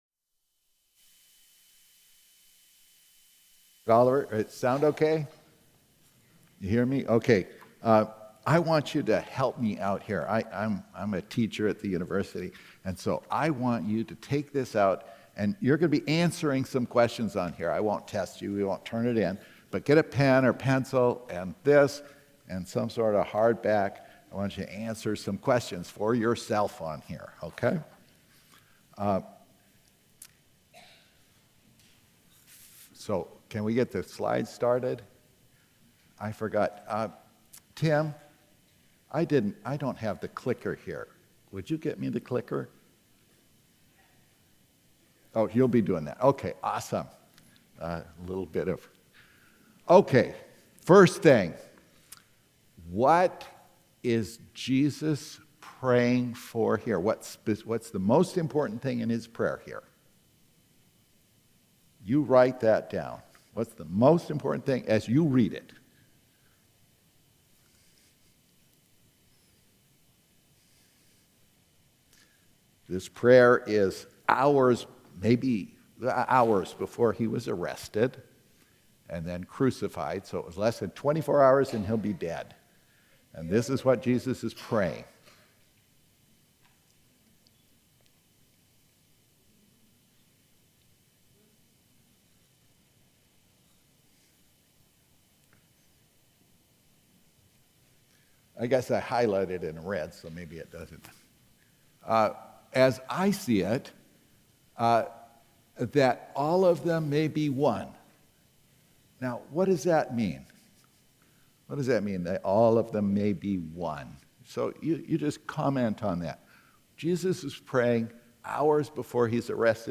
Sermons | First Alliance Church Toledo